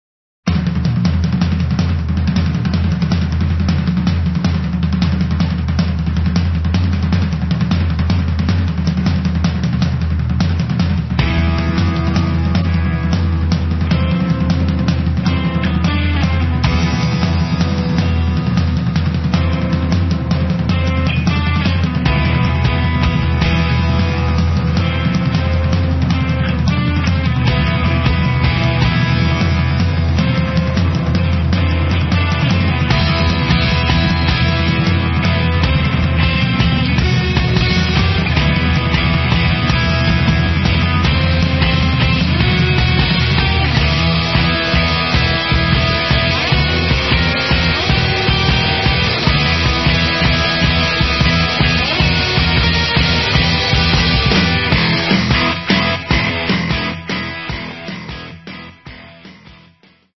spremljevalni vokali
saksofonom